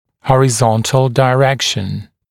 [ˌhɔrɪ’zɔntl dɪ’rekʃn] [daɪ-][ˌхори’зонтл ди’рэкшн] [дай-]горизонтальное направление